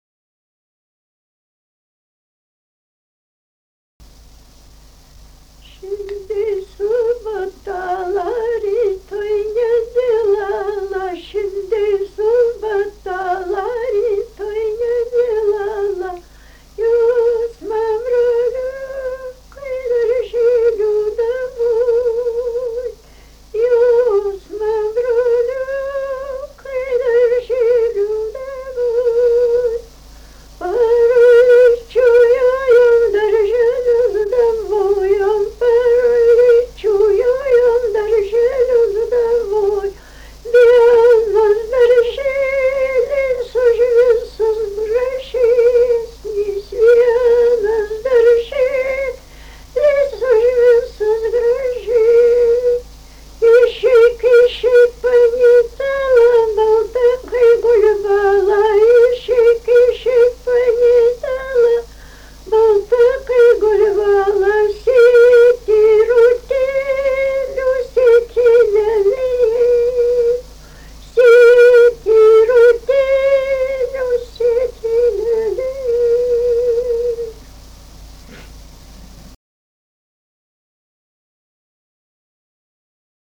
Dalykas, tema daina
Atlikimo pubūdis vokalinis
Dainuoja 2 dainininkės
2 balsai